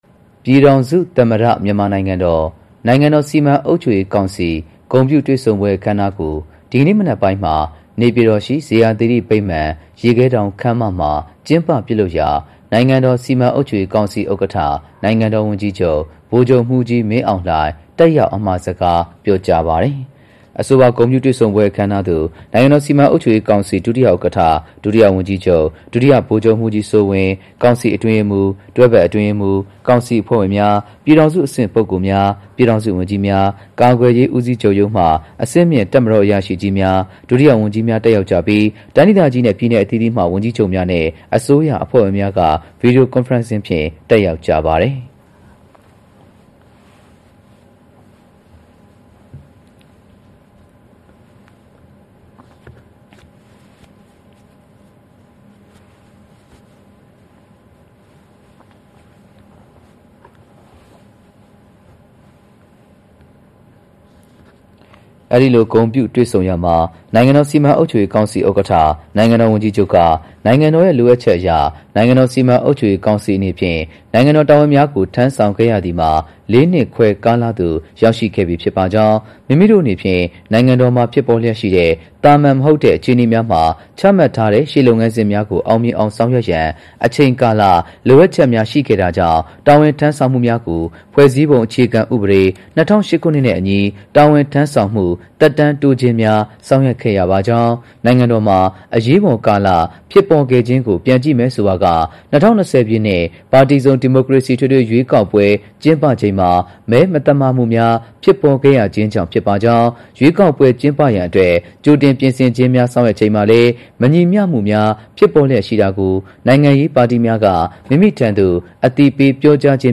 နိုင်ငံတော်စီမံအုပ်ချုပ်ရေးကောင်စီဥက္ကဋ္ဌ၊ နိုင်ငံတော်ဝန်ကြီးချုပ် ဗိုလ်ချုပ်မှူးကြီး မင်းအောင်လှိုင် ပြည်ထောင်စု သမ္မတမြန်မာနိုင်ငံတော် နိုင်ငံတော်စီမံအုပ်ချုပ်ရေးကောင်စီ ဂုဏ်ပြုတွေ့ဆုံပွဲ အခမ်းအနားသို့ တက်ရောက်အမှာစကားပြောကြား